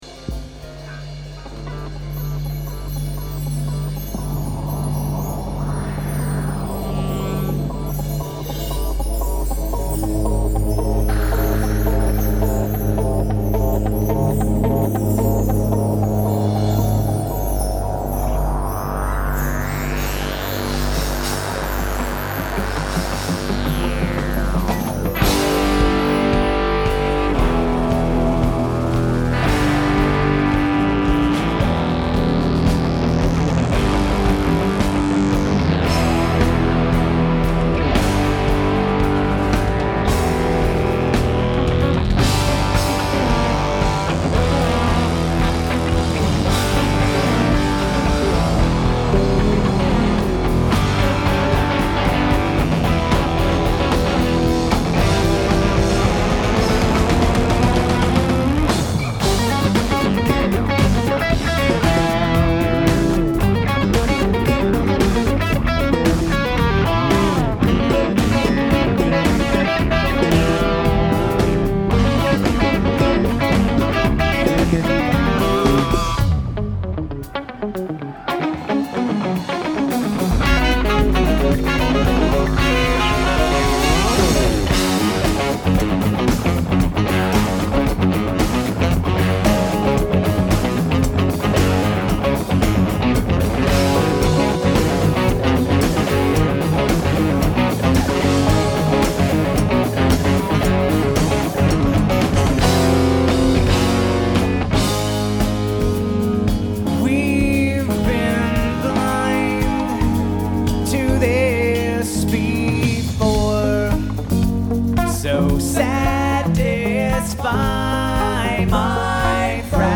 Newport Music Hall - Columbus, OH, USA